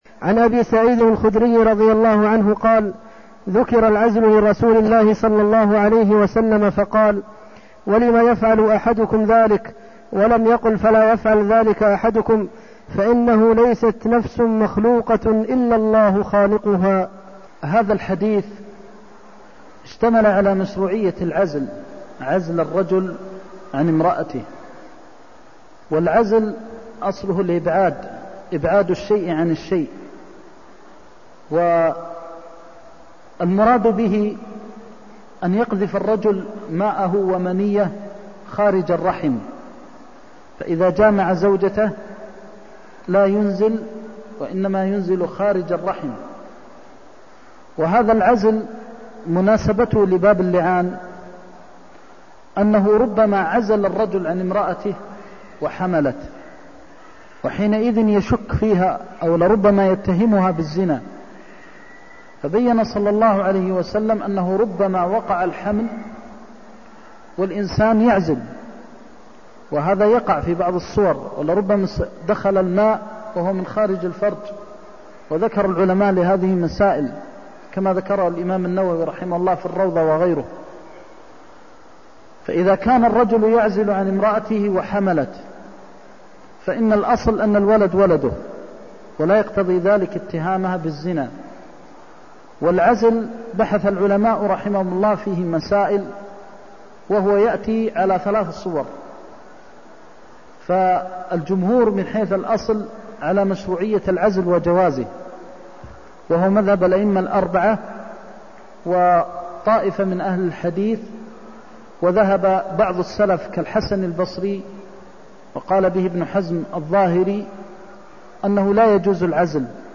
المكان: المسجد النبوي الشيخ: فضيلة الشيخ د. محمد بن محمد المختار فضيلة الشيخ د. محمد بن محمد المختار فإنه ليست نفس مخلوقة إلا الله خالقها (311)